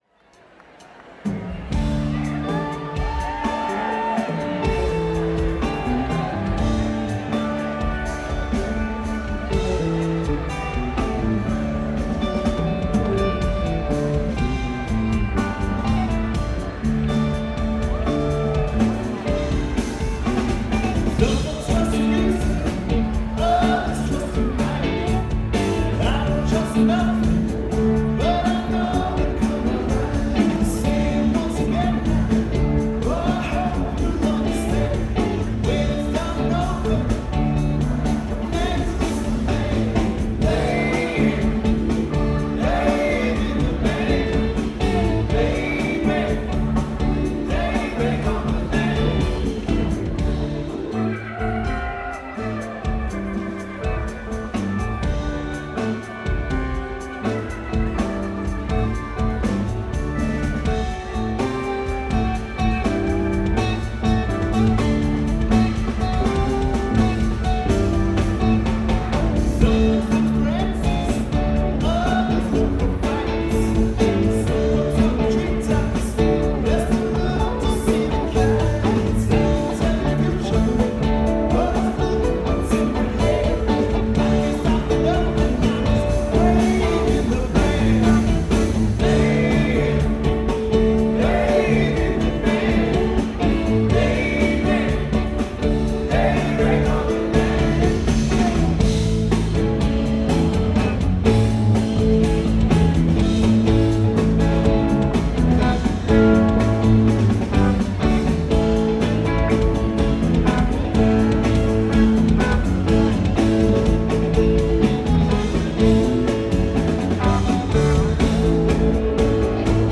Set #2 of this great New Year’s Eve show @ The Cap!
bass
drums
guitar
keys